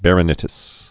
(bărə-nĭ-tĭs, bărə-nĕtĭs)